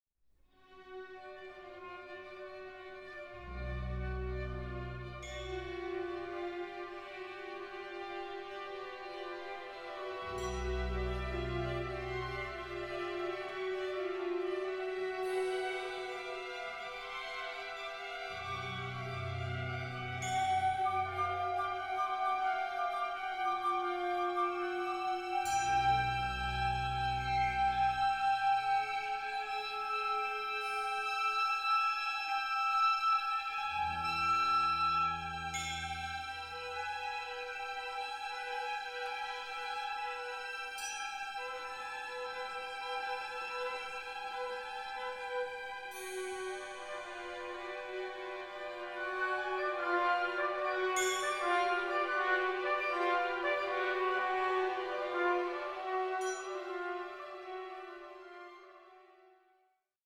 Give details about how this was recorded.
Recording: Katharina-Saal, Stadthalle Zerbst, 2025